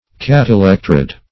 Search Result for " catelectrode" : The Collaborative International Dictionary of English v.0.48: Catelectrode \Cat`e*lec"trode\, n. [Pref. cata + elecrode.]